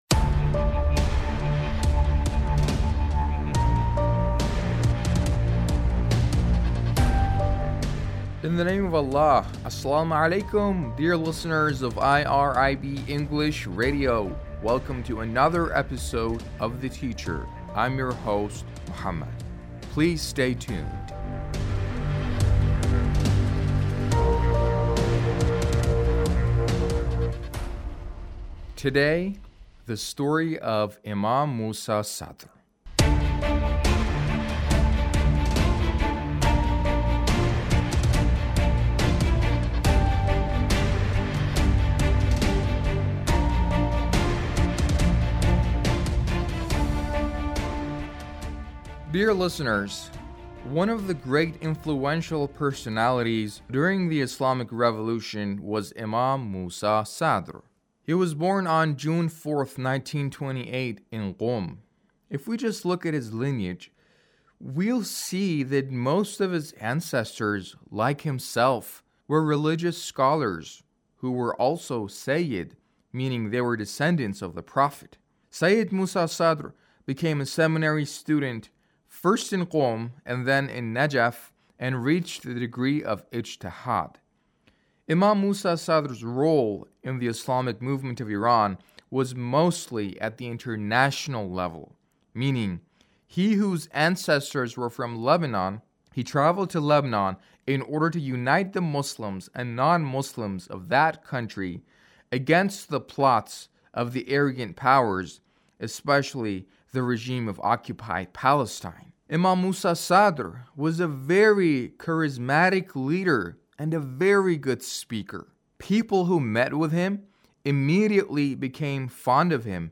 A radio documentary on the life of Imam Musa sadr